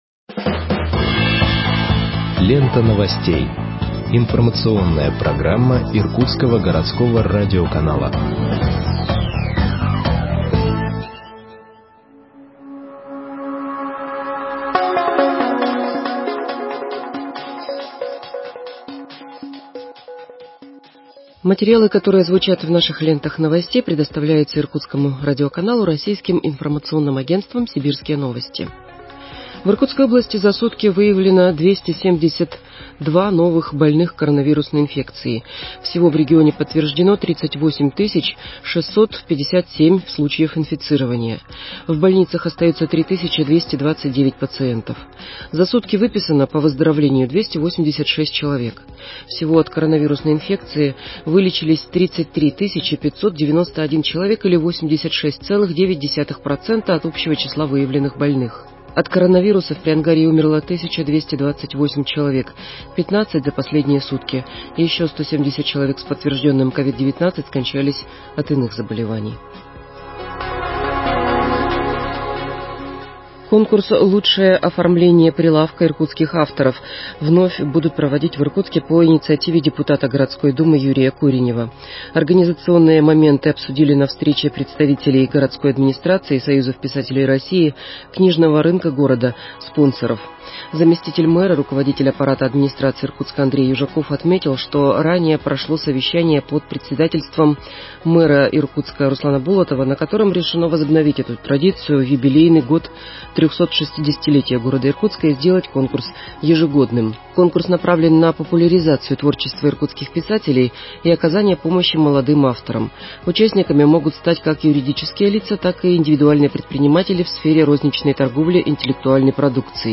Выпуск новостей в подкастах газеты Иркутск от 23.12.2020 № 2